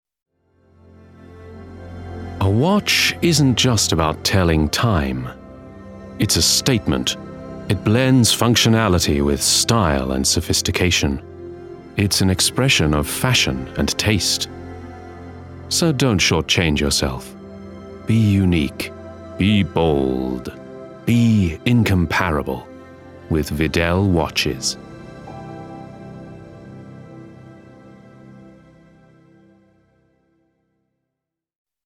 Commercial: Luxury